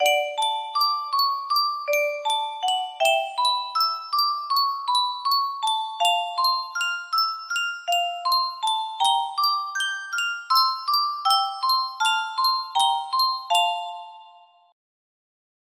Yunsheng Music Box - JR-SH2 6668 music box melody
Full range 60